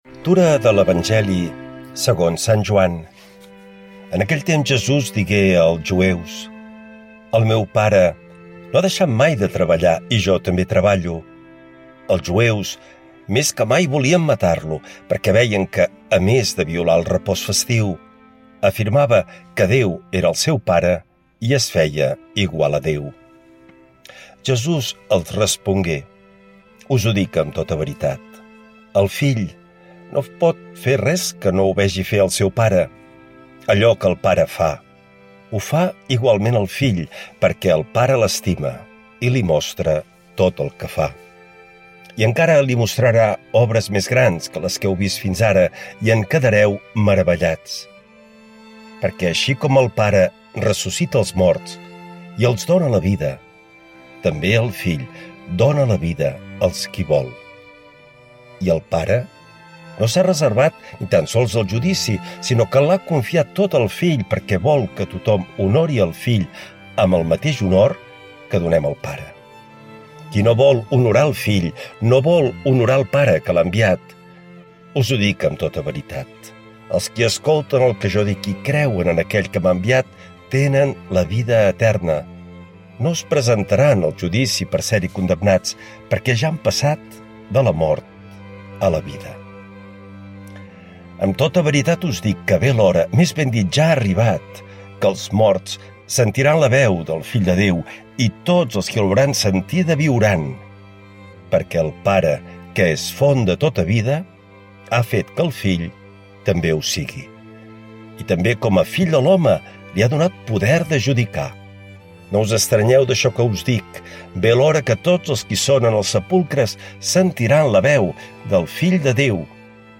L’Evangeli i el comentari de dimecres 18 març del 2026.
Lectura de l’evangeli segons sant Joan